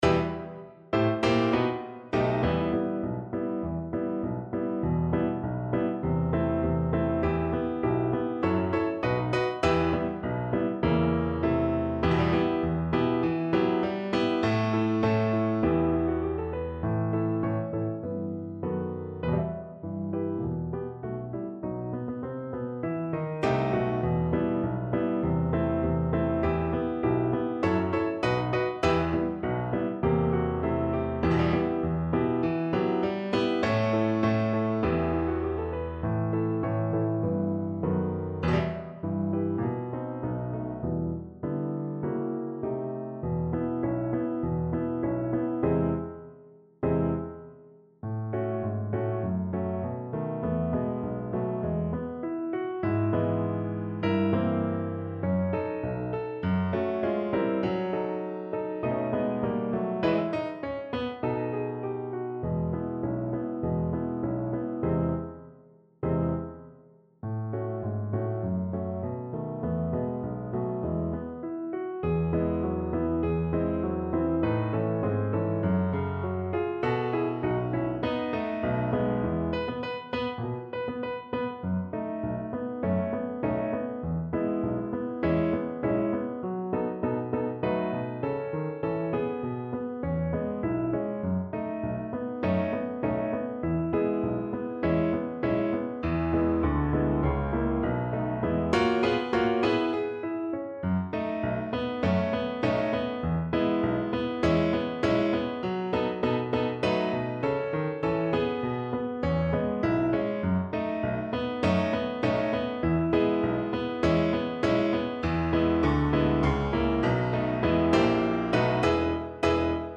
2/4 (View more 2/4 Music)
Allegro moderato (View more music marked Allegro)
Classical (View more Classical Cello Music)